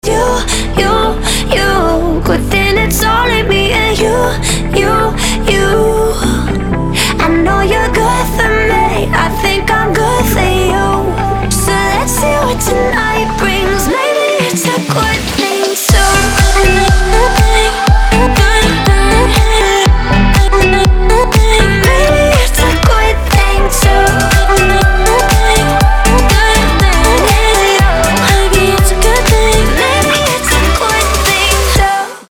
• Качество: 320, Stereo
красивые
женский вокал
dance
Electronic
EDM